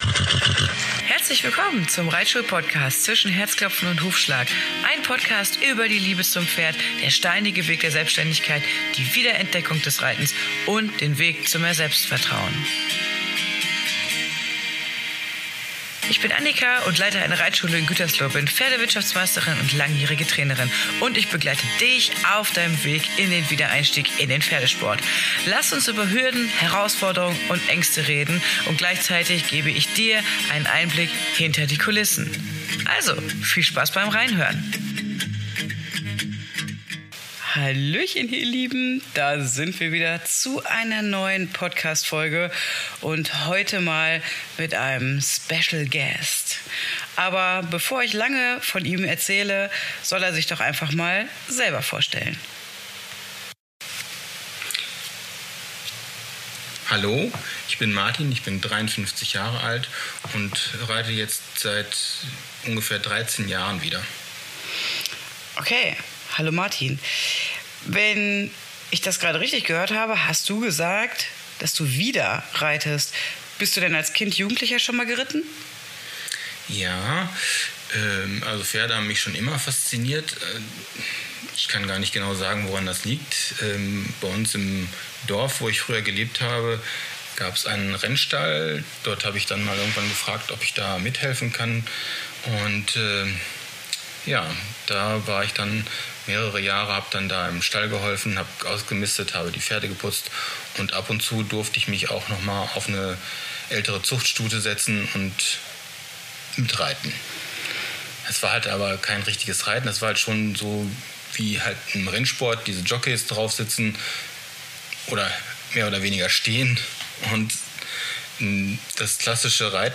Die 7. Folge ist unser erster Gasttalk.